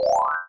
Звуковой эффект бонуса в игре (собраны очки)